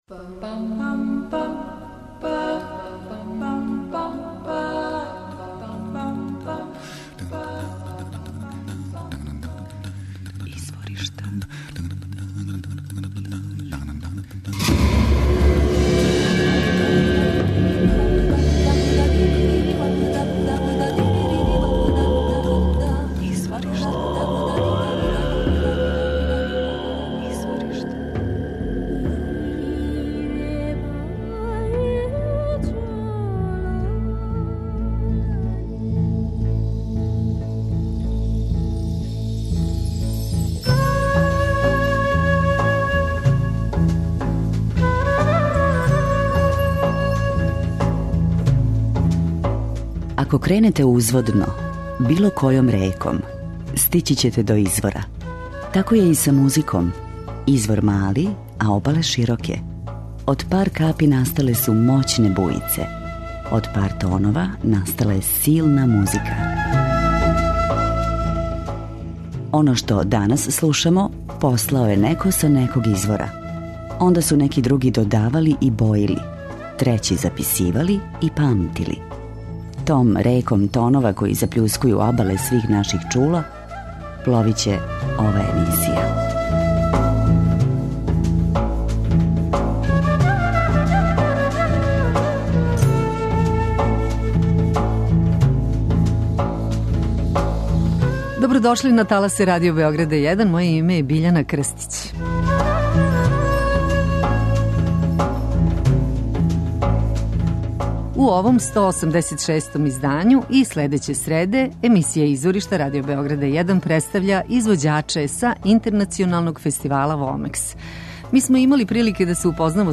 world music оркестар